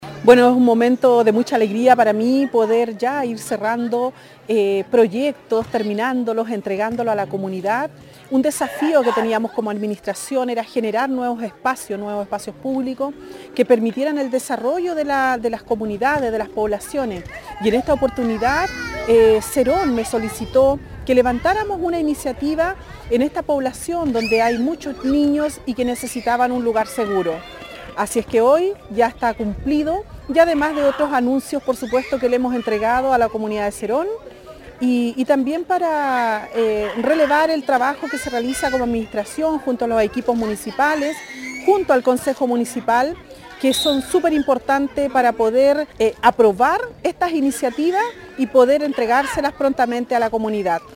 La alcaldesa Carmen Juana Olivares destacó este proyecto deportivo que permitirá el desarrollo de la comunidad de Serón.